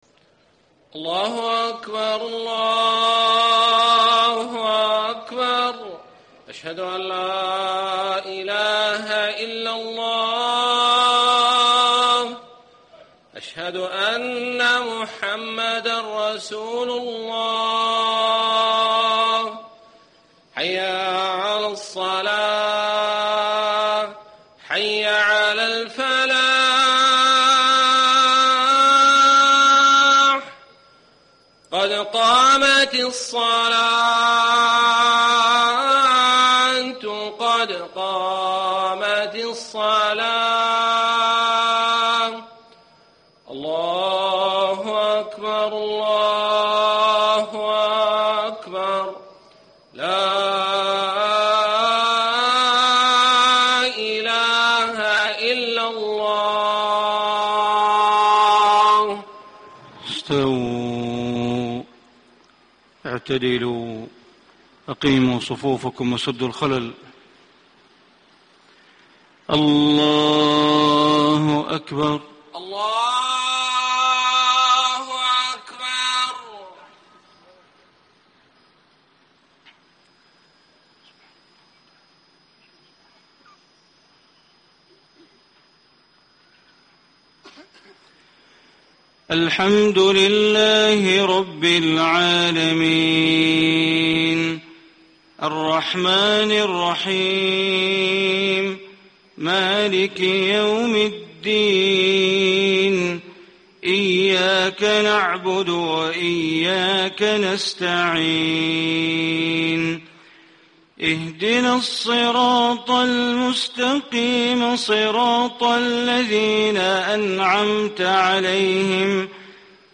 صلاة المغرب الجمعة 3 - 7 - 1435هـ من سورة المطففين > 1435 🕋 > الفروض - تلاوات الحرمين